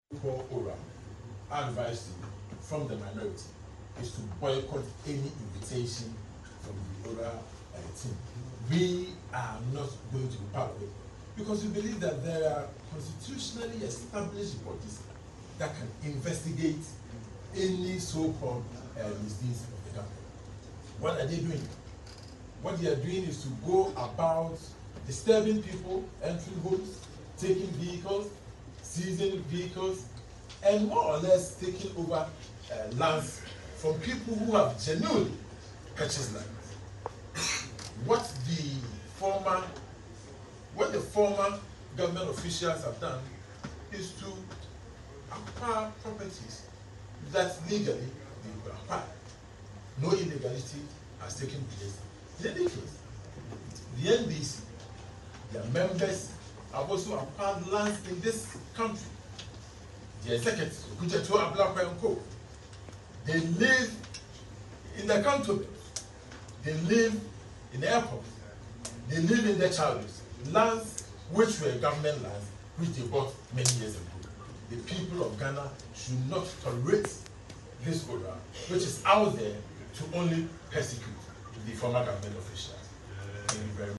The Suame MP, John Darko, made this statement at a press conference on Wednesday, January 15, 2025.